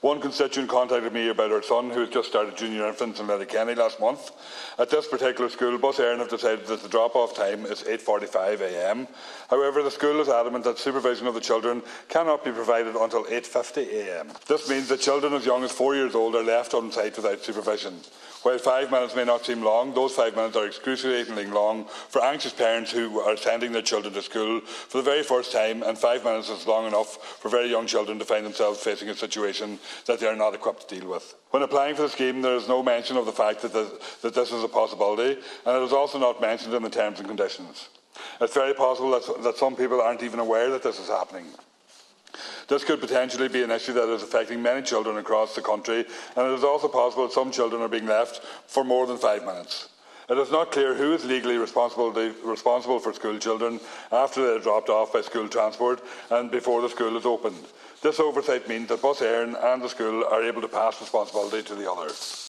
Deputy Pringle told the Dail of a mother in Letterkenny who raised her concerns after realising her junior infant is unsupervised for 5 minutes every morning: